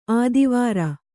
♪ ādivāra